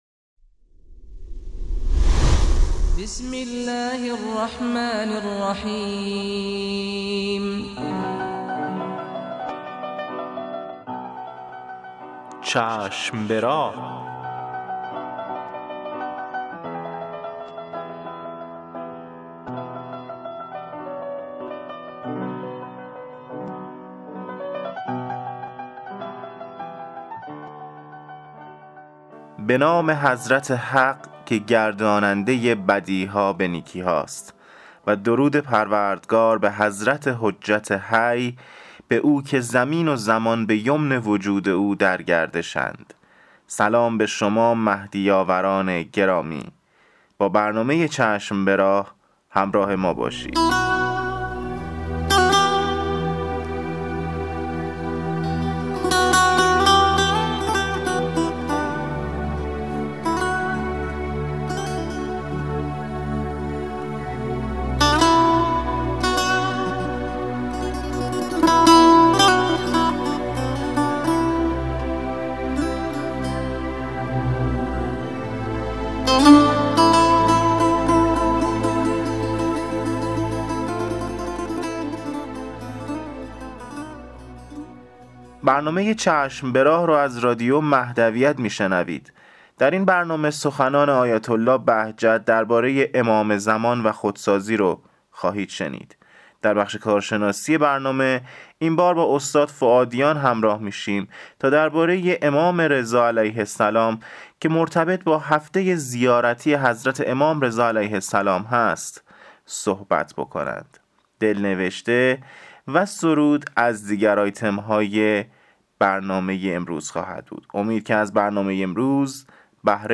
تواشیح مهدوی